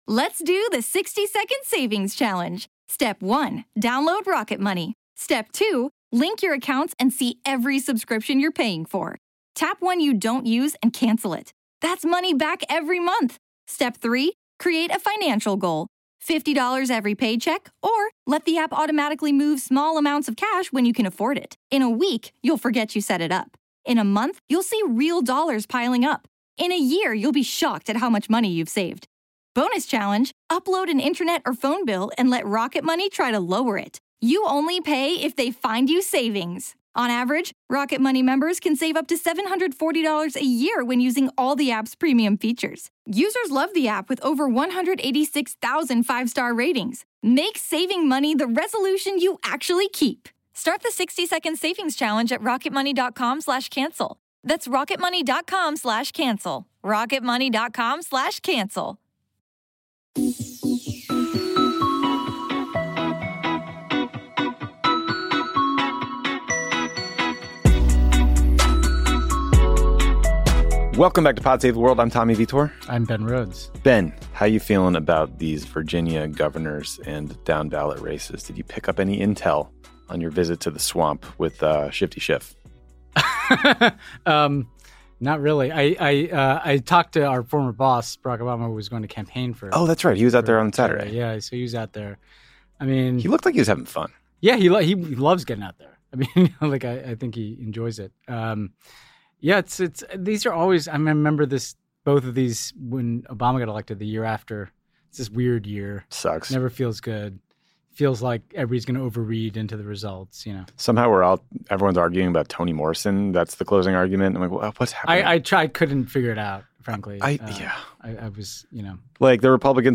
Tommy and Ben discuss the military coup in Sudan, how Boston Celtics center Enes Kanter is making waves in China, Israel’s designation of six Palestinian human rights groups as terrorist organizations, Facebook’s global impact, Trump officials get kickbacks from the Saudis, Russia, climate change and cocaine hippos. Then British Member of Parliament David Lammy joins to discuss the recent murder of a British lawmaker and the danger of online hate speech.